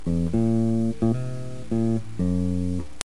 (bass2)
si_se_acabo_bass2.mp3